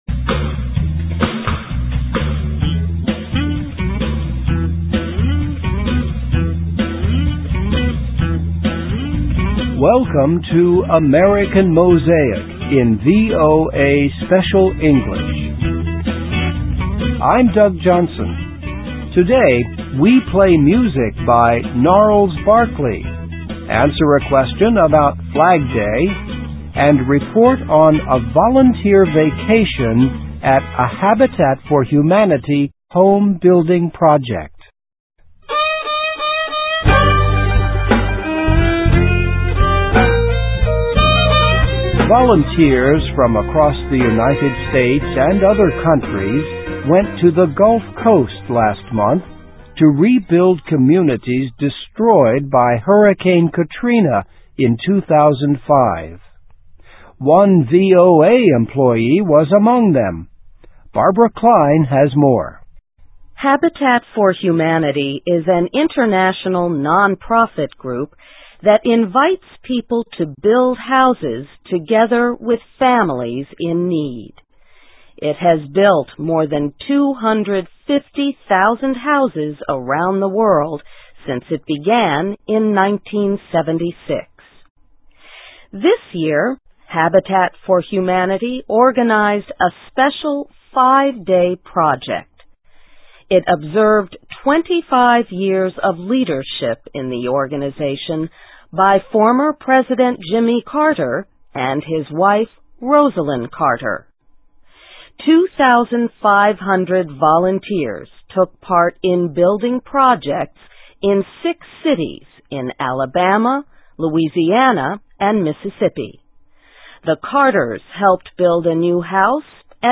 Listen and Read Along - Text with Audio - For ESL Students - For Learning English
Welcome to AMERICAN MOSAIC in VOA Special English.